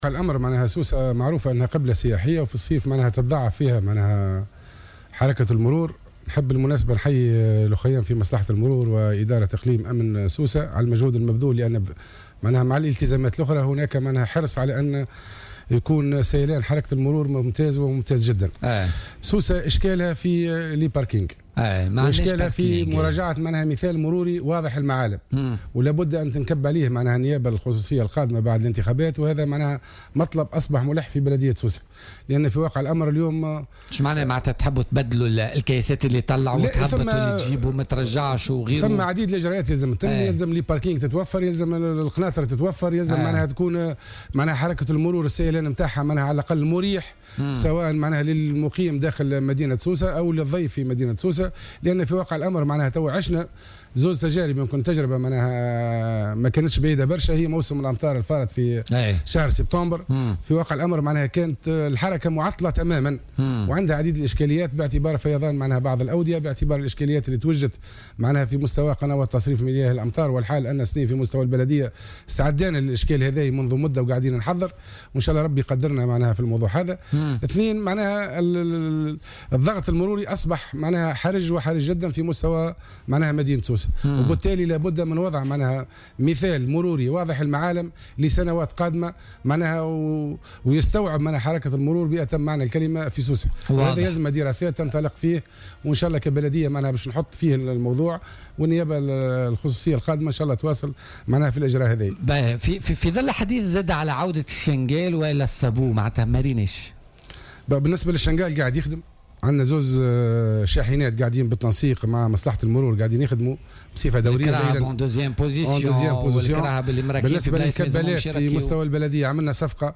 Le délégué principal de Sousse Hassine Bouchahoua, a évoqué jeudi sur Jawhara FM le lancement de plusieurs projets entrepris par le gouvernorat de Sousse.